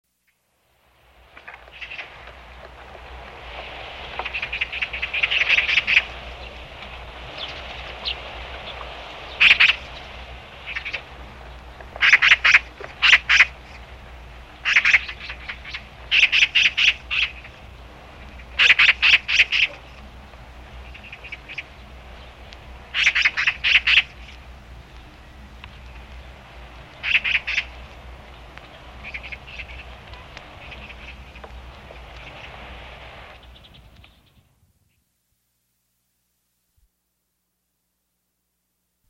까치소리
효과음